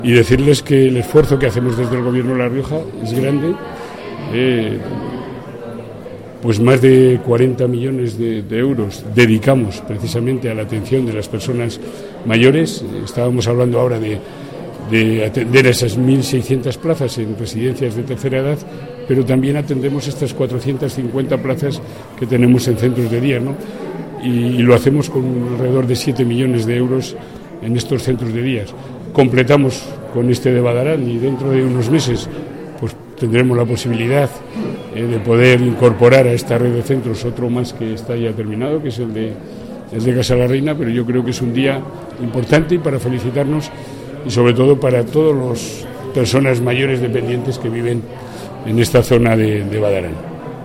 El presidente del Gobierno riojano hizo este anuncio durante la inauguración del Centro de Día de Badarán que ha provocado en Casalarreina un sentimiento de "envidia sana".